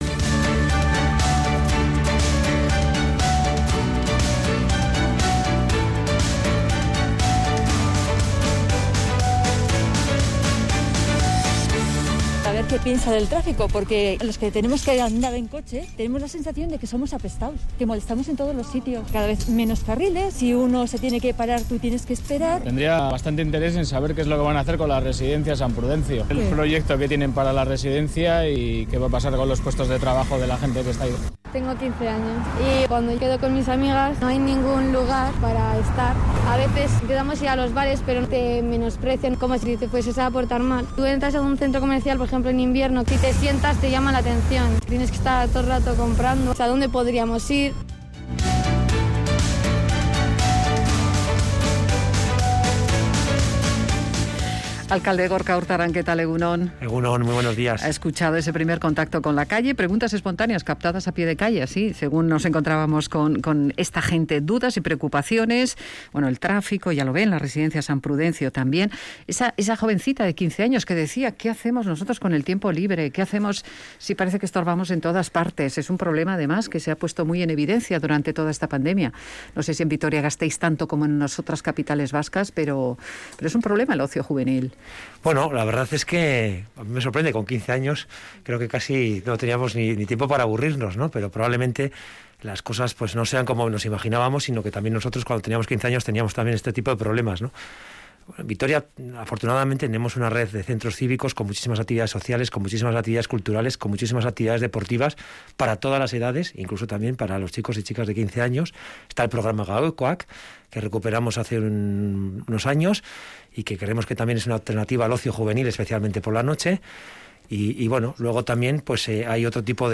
Entrevistado en Radio Vitoria, Gorka Urtaran, alcalde de Vitoria-Gasteiz, reconoce que la reforma del Iradier Arena está en la agenda de su Gobierno.